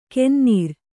♪ kennīr